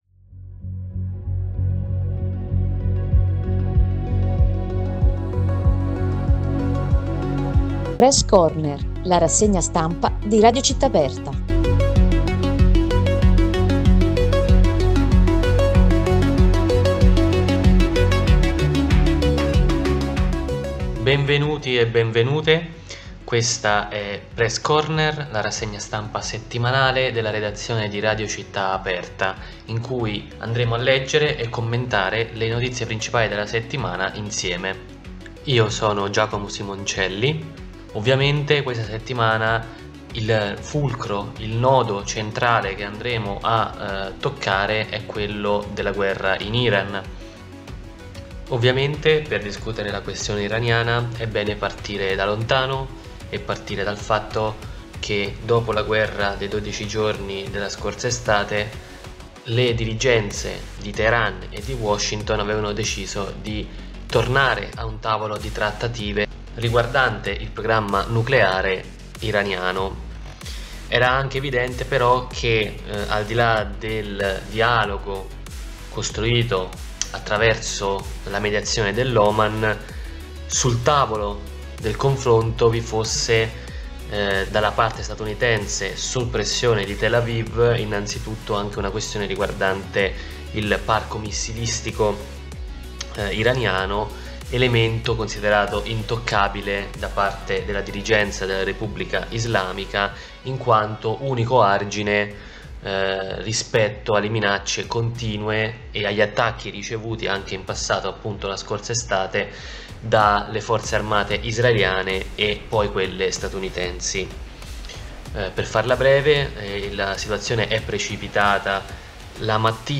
PRESS CORNER, la rassegna stampa settimanale di Radio Città Aperta – sabato 7 marzo 2026
Ogni sabato, in diretta alle 9 e poi in podcast, una selezione di notizie che raccontano i fatti più importanti della settimana.